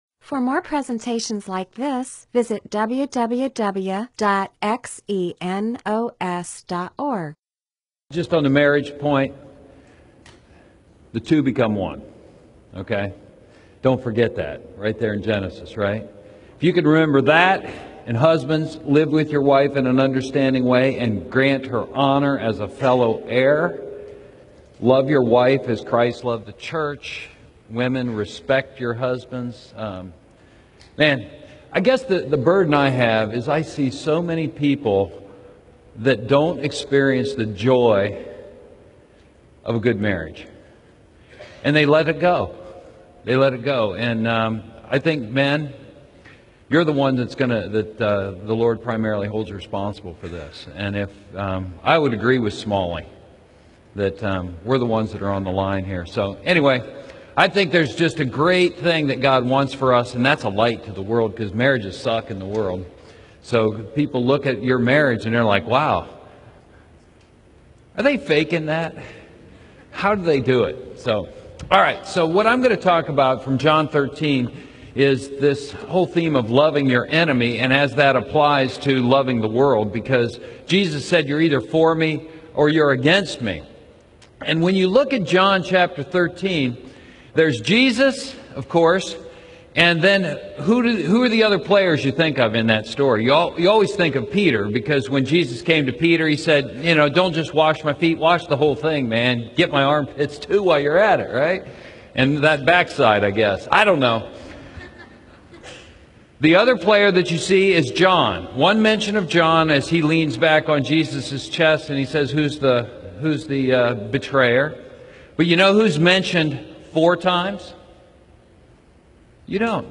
MP4/M4A audio recording of a Bible teaching/sermon/presentation about John 13:2-27; John 15:18-27.